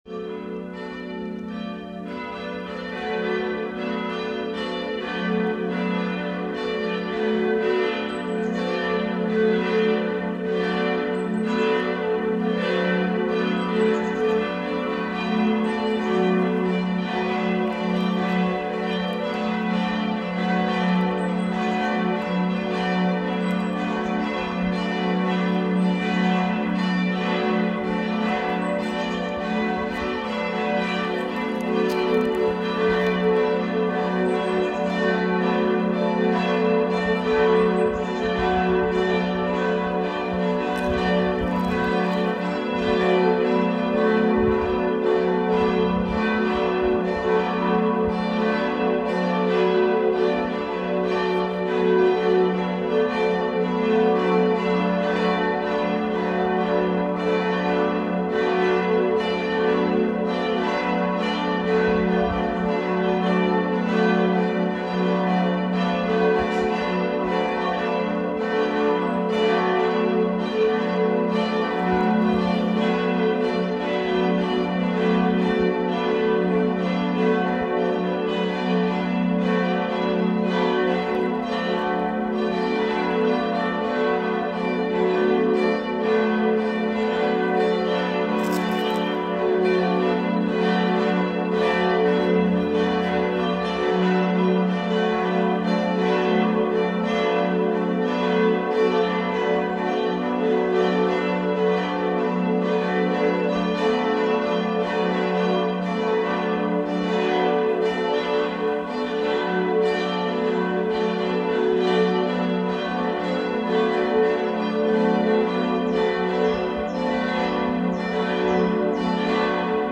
Die Glocken der Schutzengelkirche können mit unterschiedlichen Geläutemotiven erklingen.
Die sehr gute Harmonie, die hohe Vibrationsenergie der Glocken in Zusammenwirkung der schönen Akustik der Glockenstube ergeben eine Klangwirkung von außerordentlicher Klarheit, Fülle und Beseelung.
Geläutemotiv Christ ist erstanden (zum Osterfest):
Christkönig-Glocke, Marien-Glocke, Joseph-Glocke, Schutzengel-Glocke